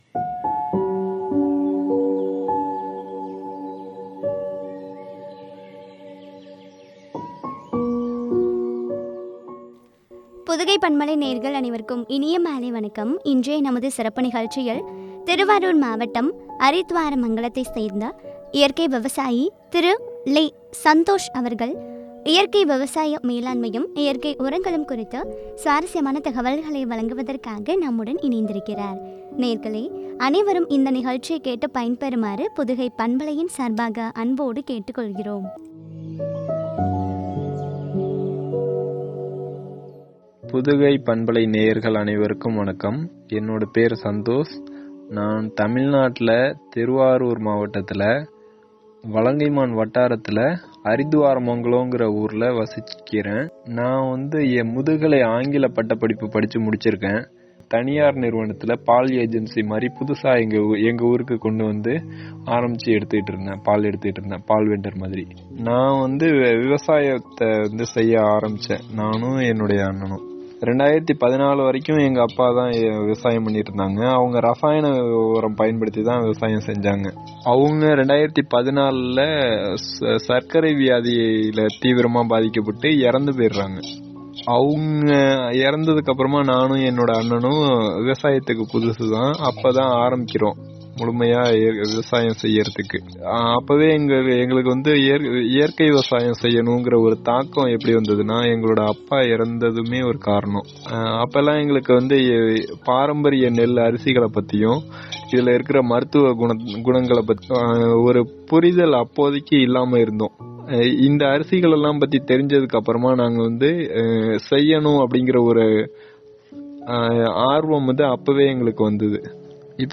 இயற்கை உரங்களும் பற்றிய உரையாடல்.